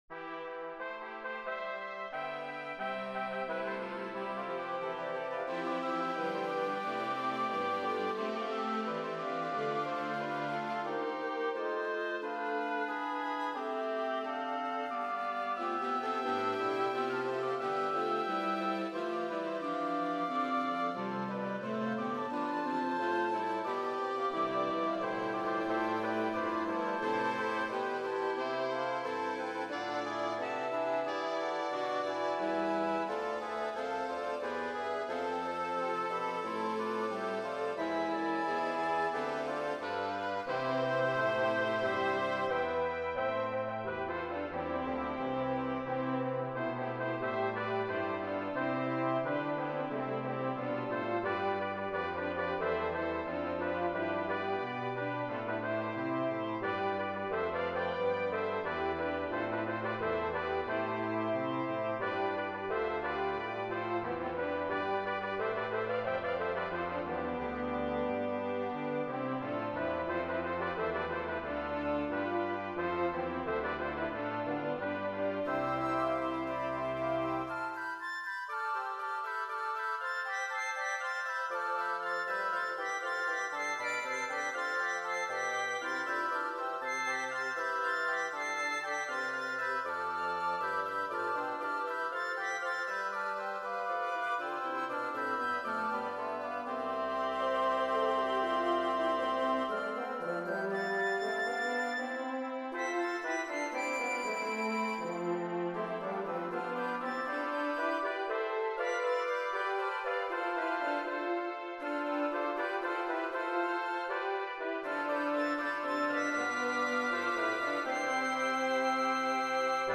Processional/Recessional
Piccolo, Flute, Bb Clarinet, Oboe, Bassoon
Trumpets 1,2; French Horn, Trombone 1, Trombone 2 Euphonium, Tuba
Violins 1,2; Viola, Cello, Bass
Alto, Tenor, Baritone Saxophone
Vihüela, Guitarrón
Percussion